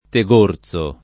[ te g1 r Z o ]